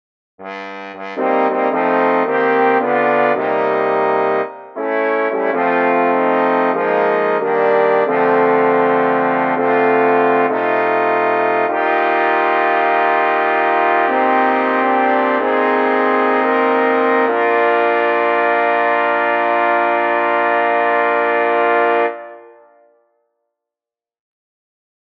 Key written in: B♭ Major
How many parts: 4
Type: Barbershop
All Parts mix: